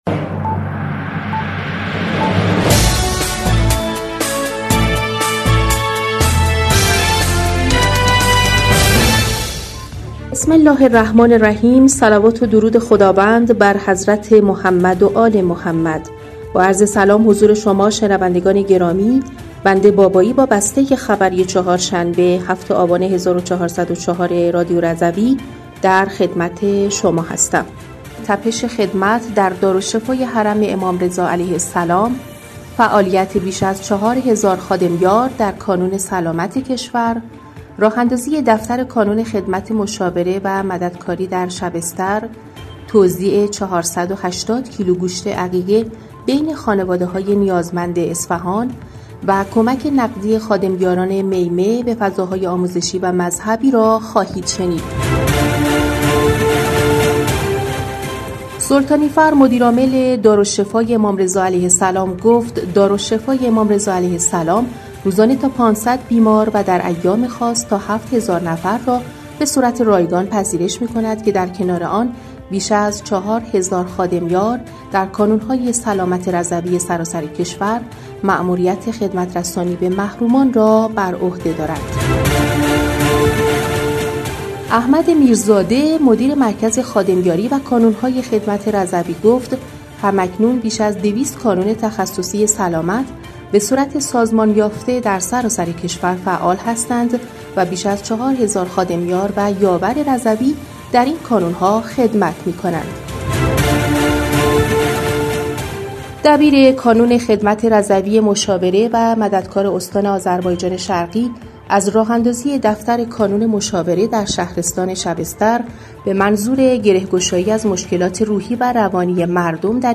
بسته خبری ۷ آبان ۱۴۰۴ رادیو رضوی؛